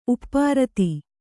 ♪ uppārati